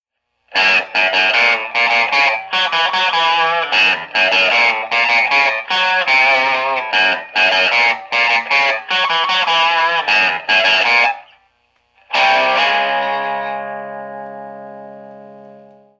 描述：故意的怪异。时髦的Tele声音来自DiMarzio拾音器和Lab Series L7，这是与1953年Telecaster和Vox AC30放大器最接近的匹配。非常老的Led Zeppelin。
Tag: 120 bpm Funk Loops Guitar Electric Loops 1.35 MB wav Key : F